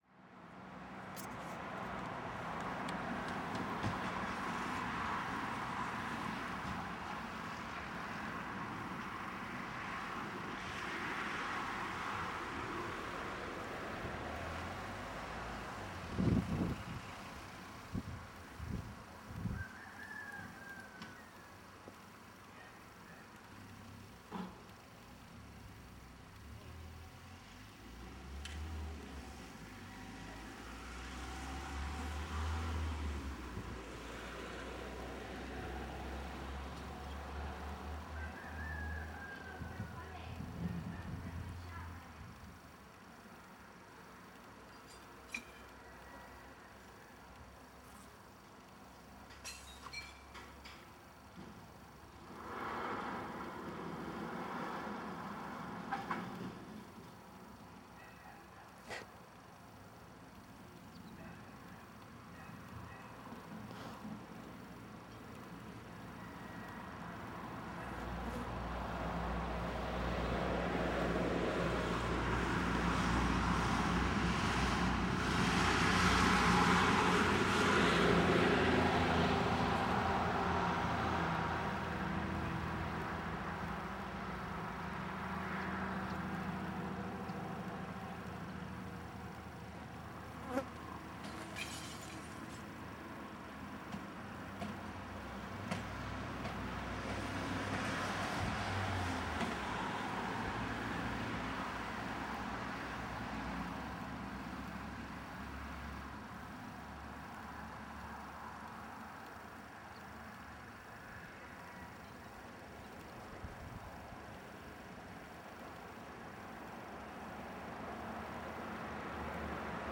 Cova da Laghoa. Toma dende o fondo